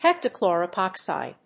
heptachlor+epoxide.mp3